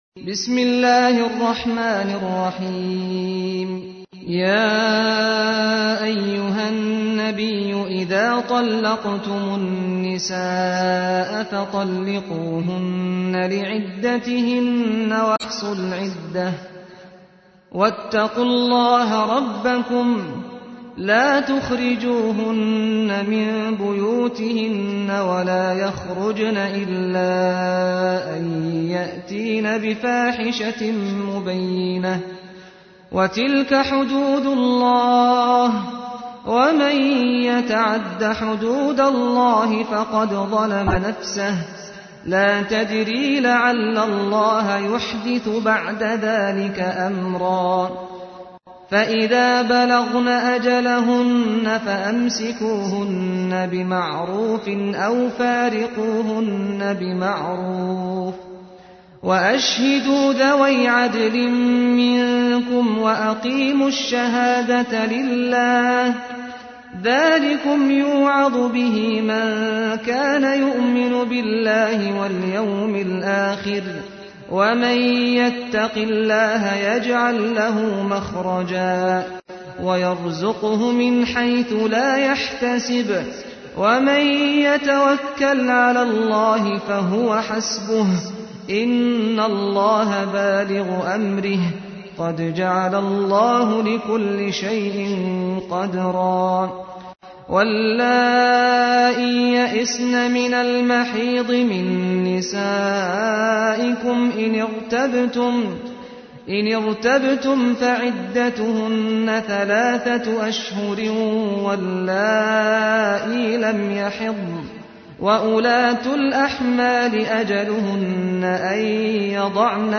تحميل : 65. سورة الطلاق / القارئ سعد الغامدي / القرآن الكريم / موقع يا حسين